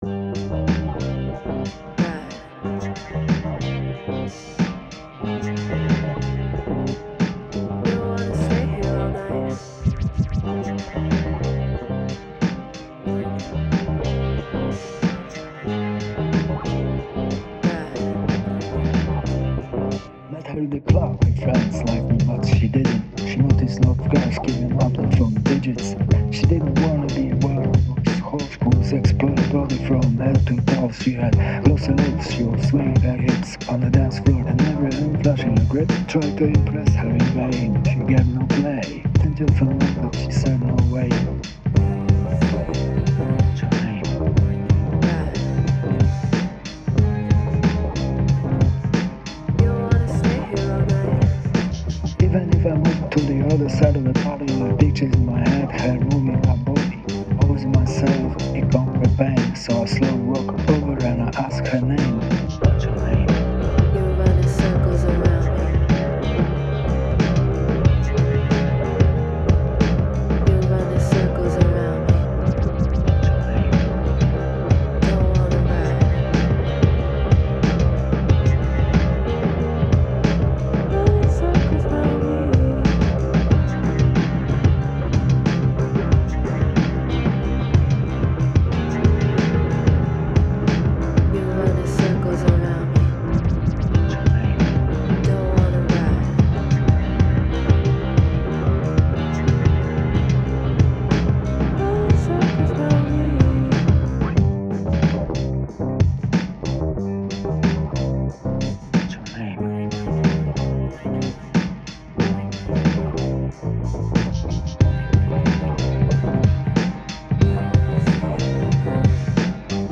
In the end it's barely audible :)
Trip Hop